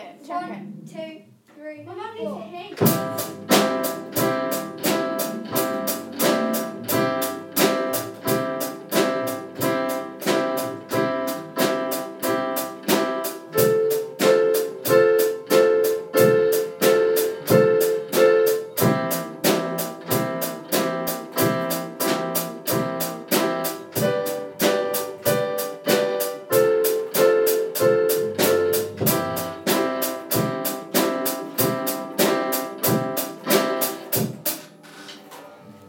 live blues recording 1